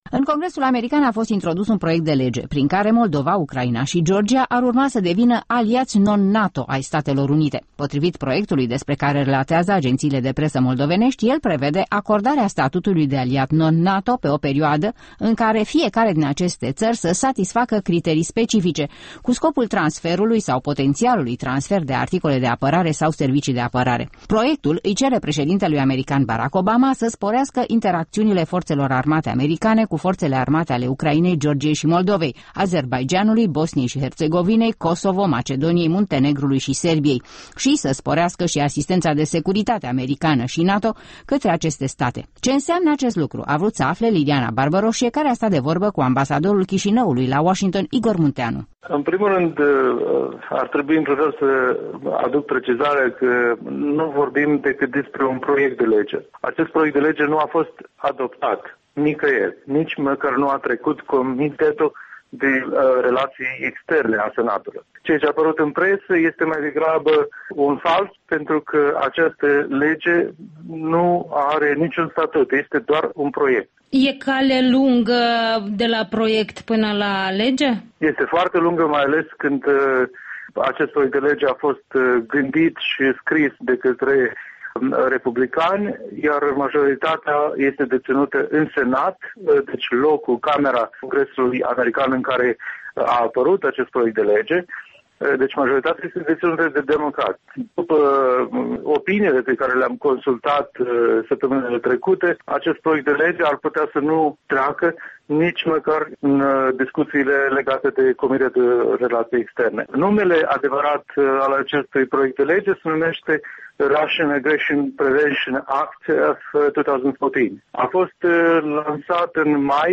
Igor Munteanu, ambasadorul R. Moldova la Washington răspunde întrebărilor Europei Libere